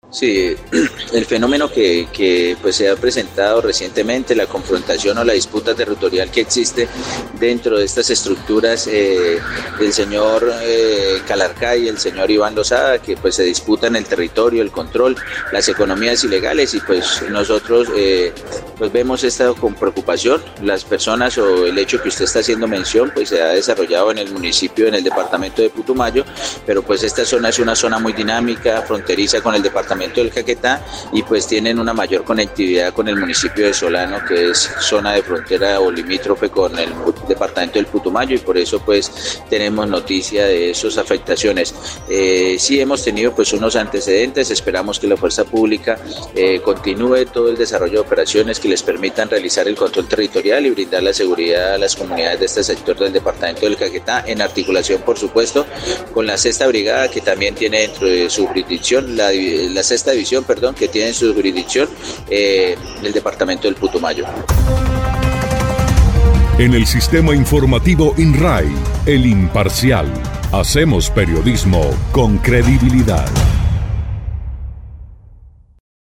Así lo dio a conocer el secretario de gobierno seccional, Arturo Perdomo Granja, quien dijo que el tema preocupa por las repercusiones que se puedan generar tras estos combates por el control territorial.